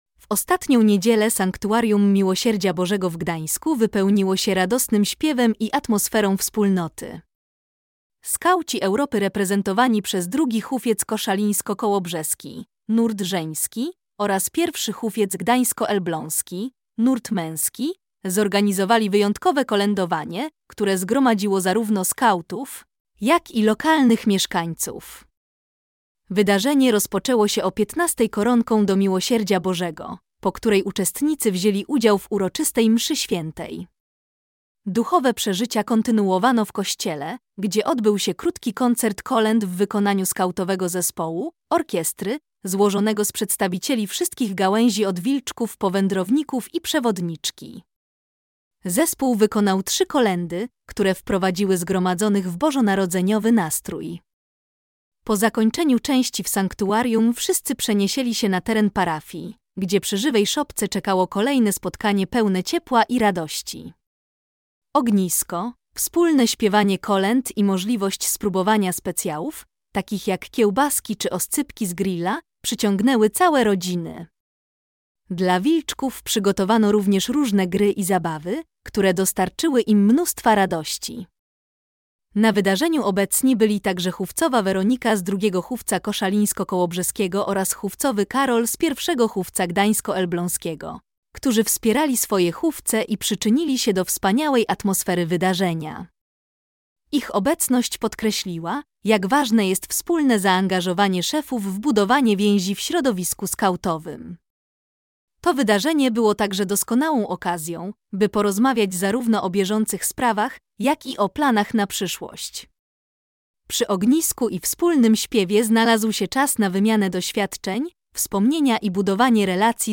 W ostatnią niedzielę (5 stycznia 2025) Sanktuarium Miłosierdzia Bożego w Gdańsku wypełniło się radosnym śpiewem i atmosferą wspólnoty.
Duchowe przeżycia kontynuowano w kościele, gdzie odbył się krótki koncert kolęd w wykonaniu skautowego zespołu (orkiestry) złożonego z przedstawicieli wszystkich gałęzi – od wilczków po wędrowników i przewodniczki. Zespół wykonał trzy kolędy, które wprowadziły zgromadzonych w bożonarodzeniowy nastrój.
Koledowanie-Skautow-Europy-w-Gdansku-2025.mp3